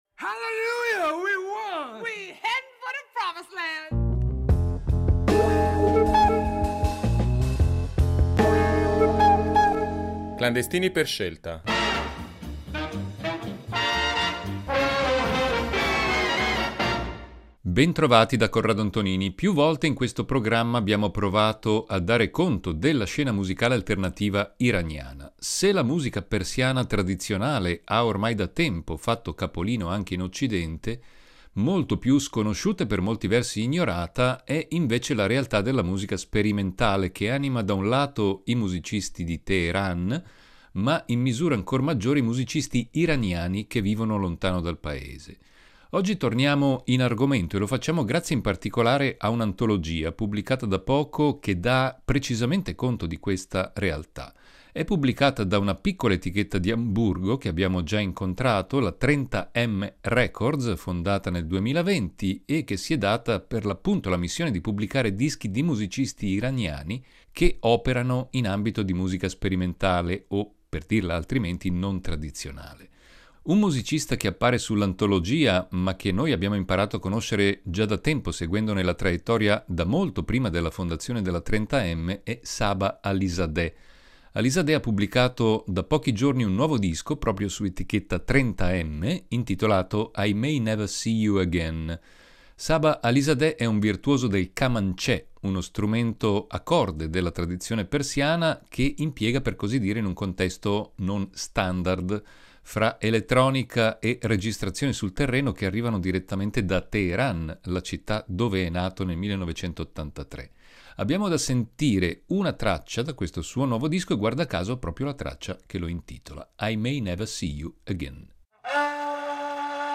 “Clandestini per scelta” questa settimana dedica la sua attenzione alla scena musicale sperimentale di Teheran, una scena ricchissima e ancora poco o nulla conosciuta in Occidente.